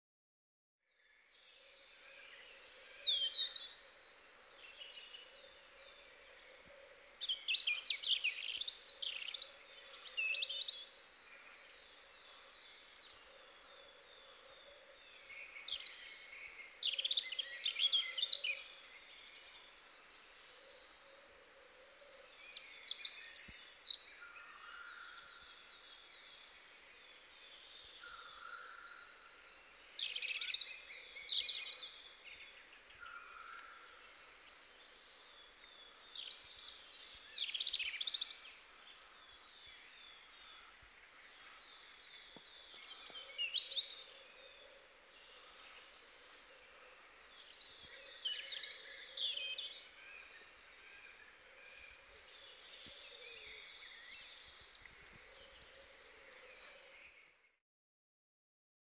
Punarinta
Laulu: Kaunis soliseva ja hieman surumielinen laulu, jossa heleitä ääniä.